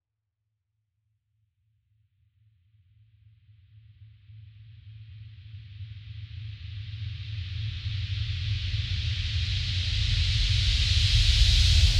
Sizzle.wav